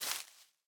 Minecraft Version Minecraft Version snapshot Latest Release | Latest Snapshot snapshot / assets / minecraft / sounds / block / leaf_litter / place1.ogg Compare With Compare With Latest Release | Latest Snapshot